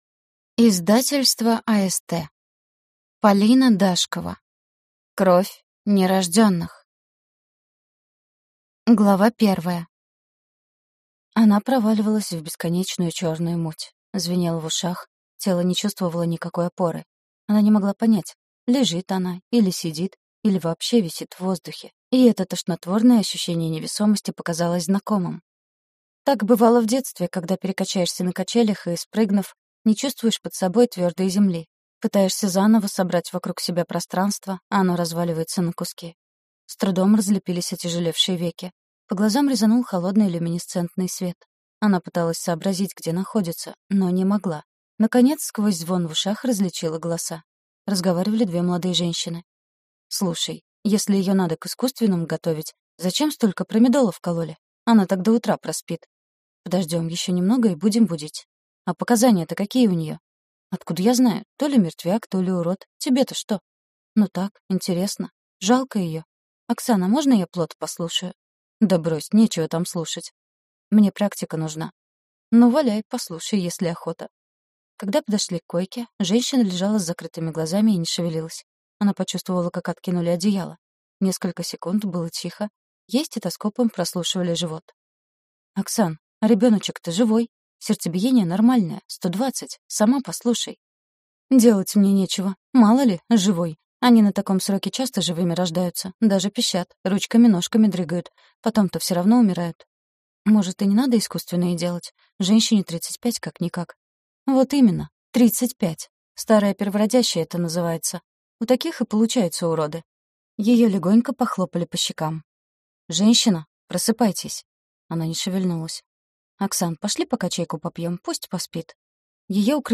Аудиокнига Кровь нерожденных | Библиотека аудиокниг